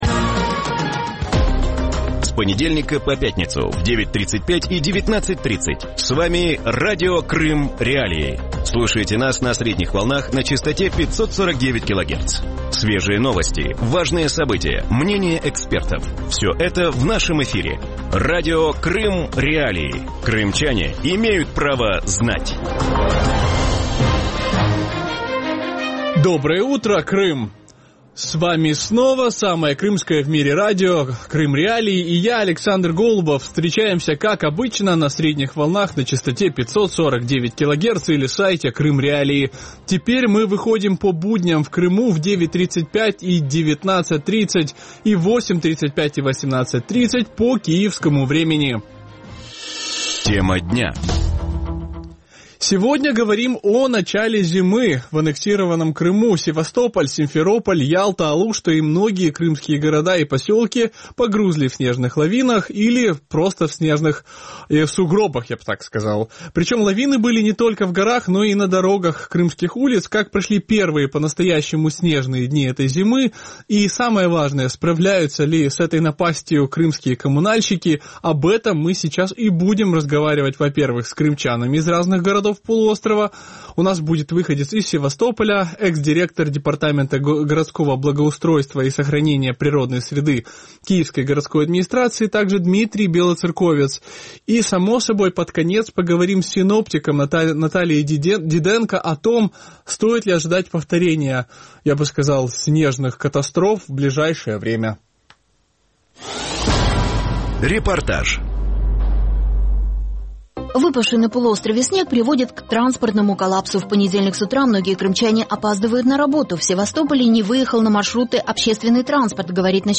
Утром в эфире Радио Крым.Реалии говорят о начале зимы в аннексированном Крыму.
Радио Крым.Реалии выходит по будням в 8:35 и 18:30 (9:35 и 19:30 в Крыму) на частоте 549 килогерц и на сайте Крым.Реалии. Крымчане могут бесплатно звонить в эфир по телефону 8 8